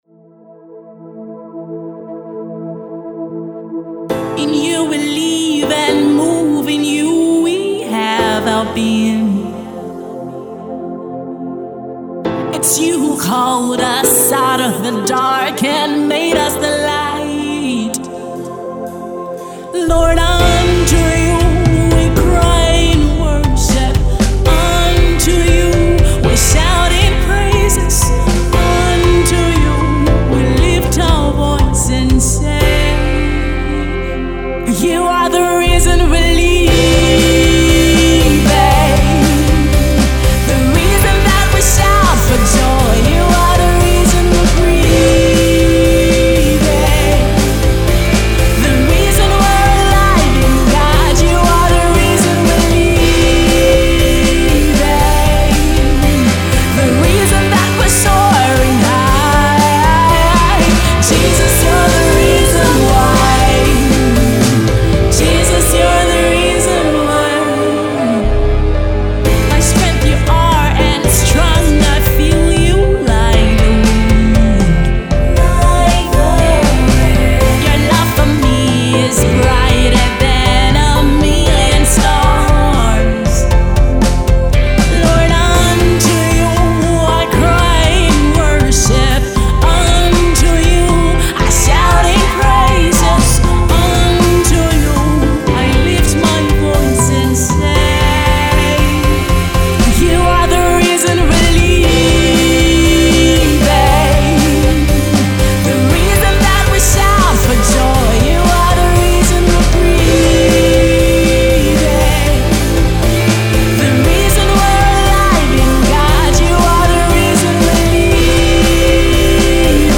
rock worship song